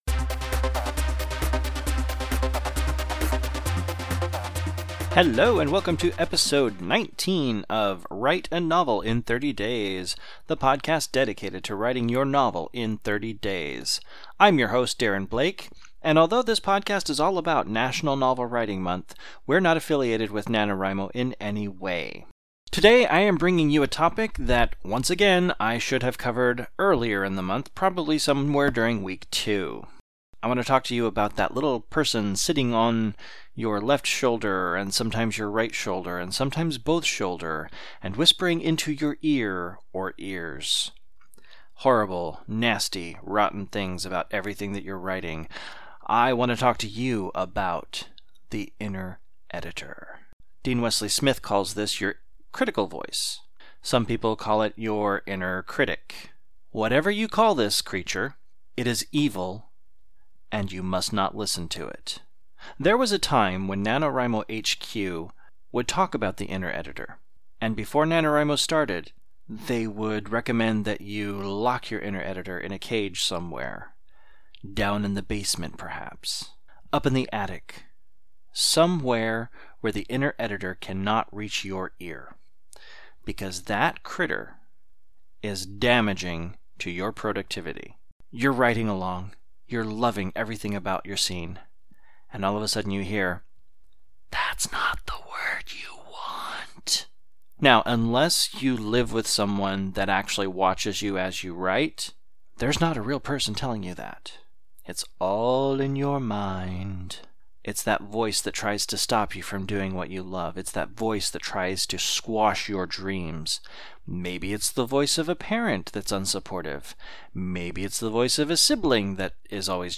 Music: “Raving Energy (faster)”